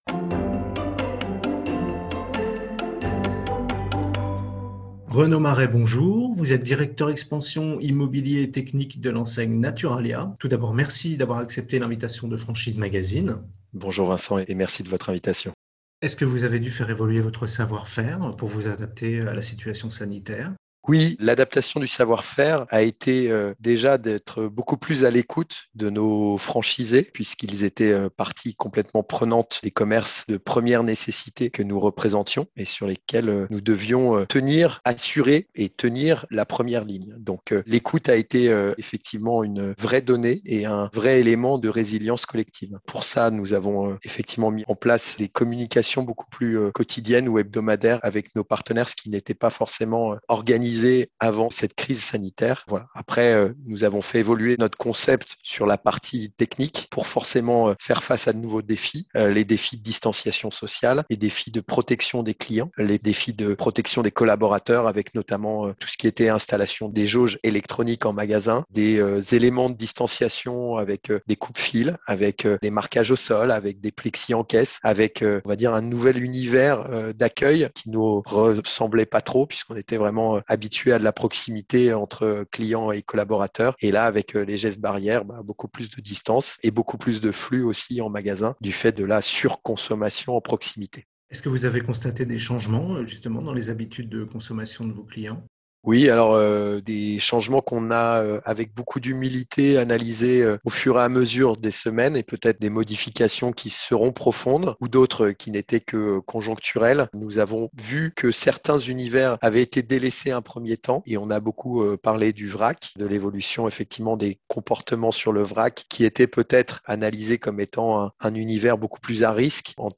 Au micro du podcast Franchise Magazine : la Franchise Naturalia - Écoutez l'interview